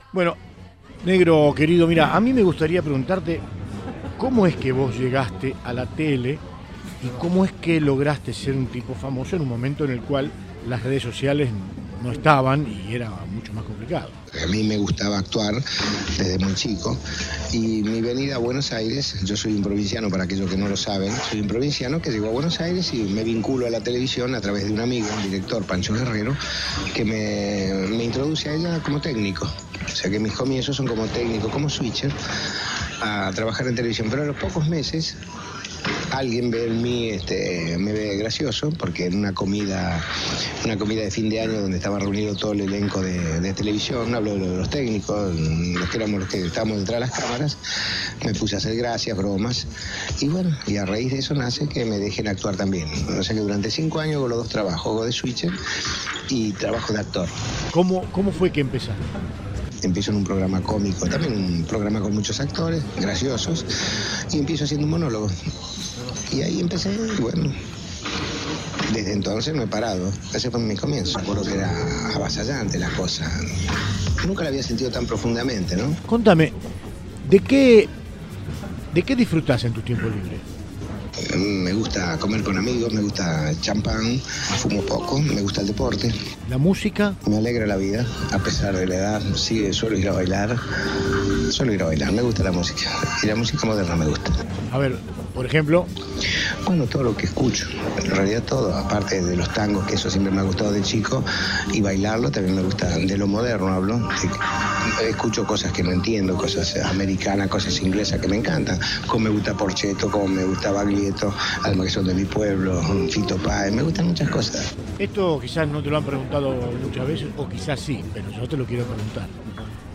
Olmedo eterno: entrevista al 'Negro' desde el más allá en Viva la Radio